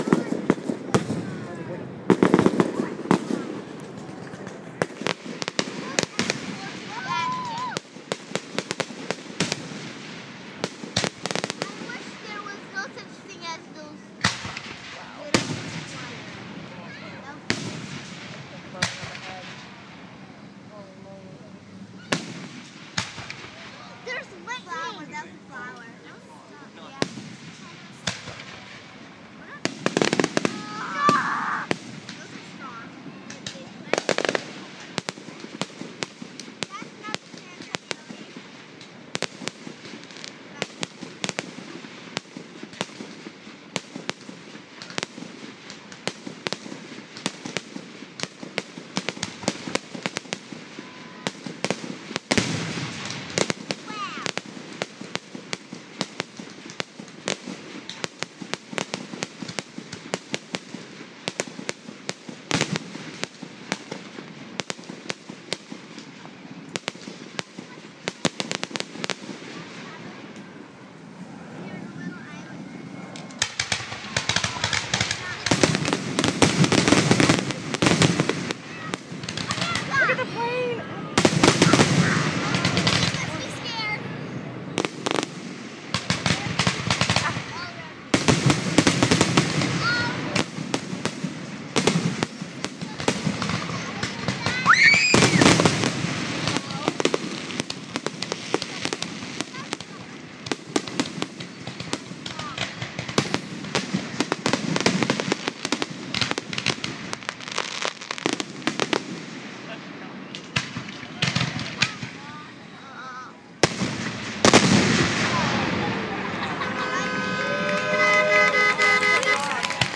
Fireworks, last few minutes and finale